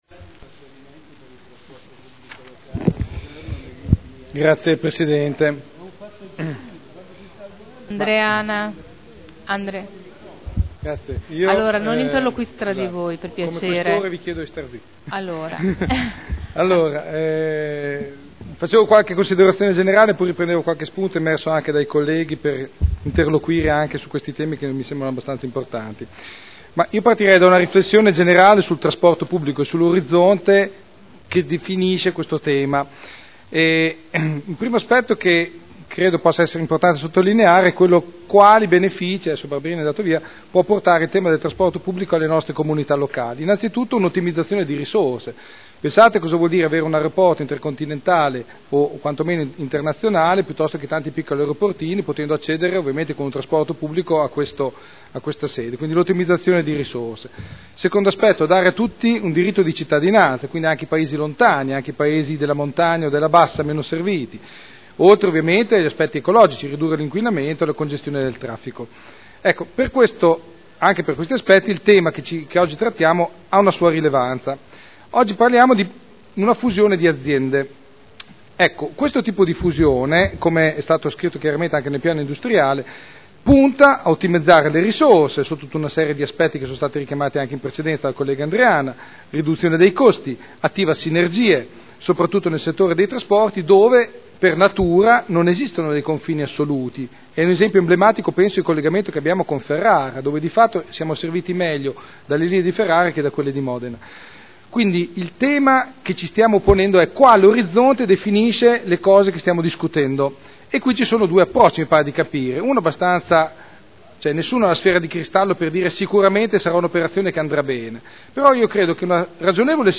Seduta del 3 ottobre 2011 Proposta di deliberazione: Aggregazione dei soggetti affidatari del Servizio di Trasporto Pubblico Locale nei bacini di Modena, Reggio Emilia e Piacenza Dibattito su proposta di delibera e odg presentati in corso del Consiglio Comunale